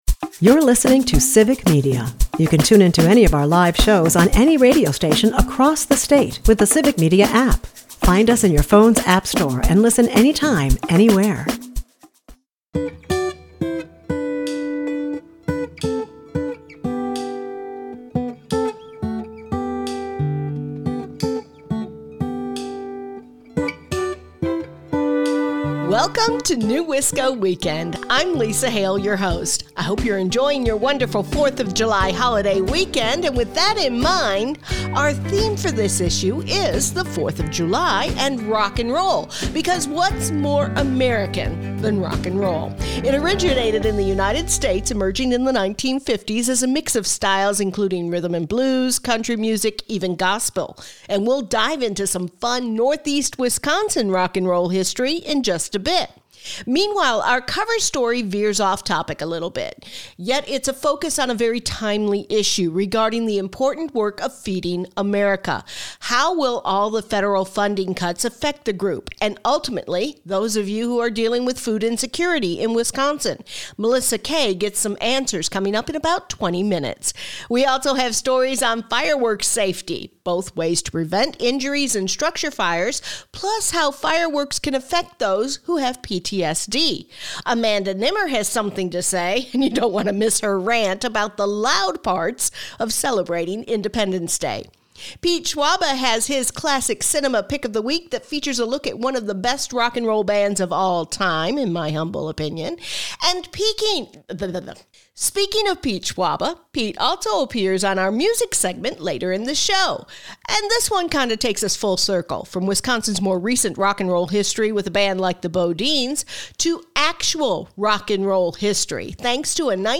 It’s the Fourth of July holiday weekend, and NEWisco Weekend is in the spirit of things!
NEWisco Weekend is a part of the Civic Media radio network and airs Saturdays at 8 am and Sundays at 11 am on 97.9 WGBW and 98.3 and 96.5 WISS.